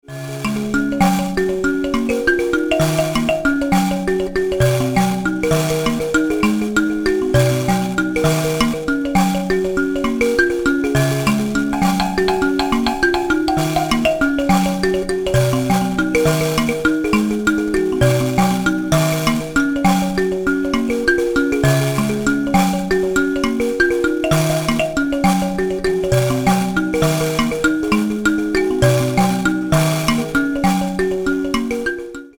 G# nyamaropa tuning.